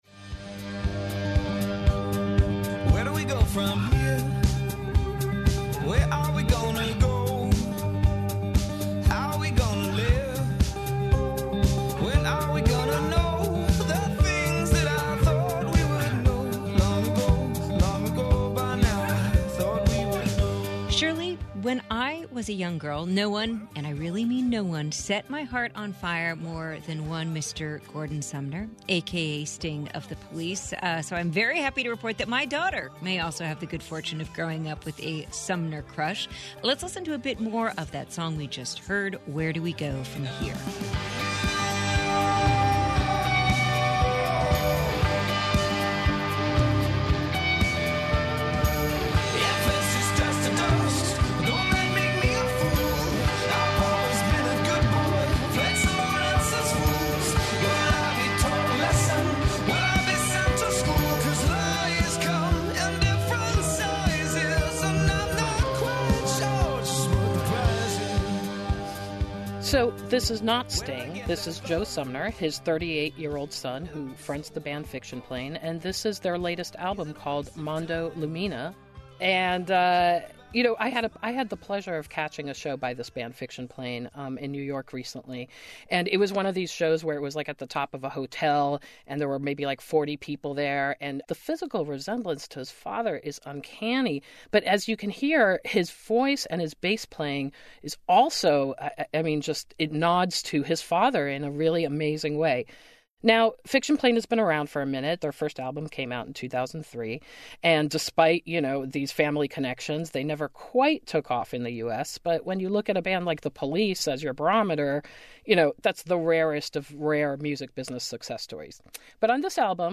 Sweet sax prevails on this episode of Tuesday Reviewsday.